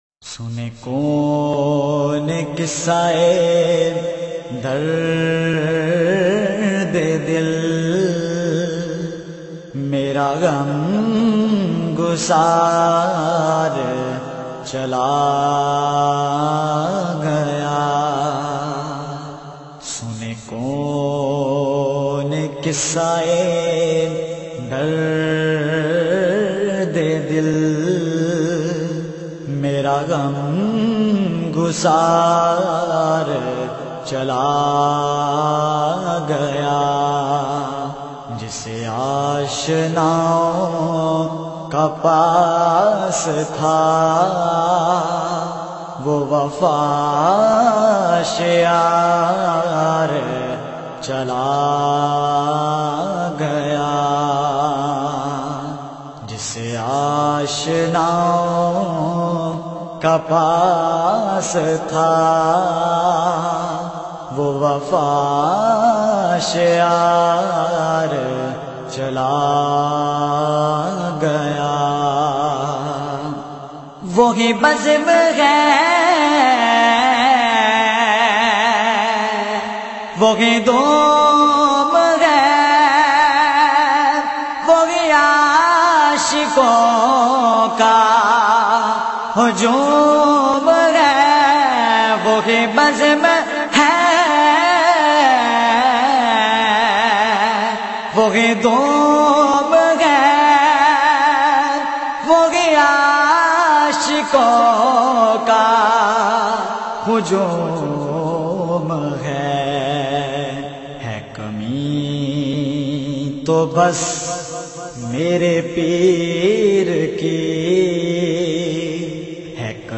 Naat